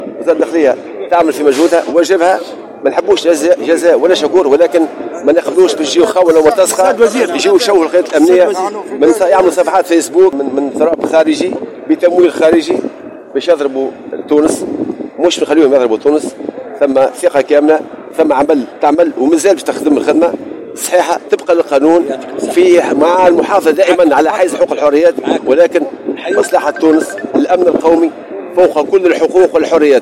وقال وزير الداخلية لمراسلنا بالعاصمة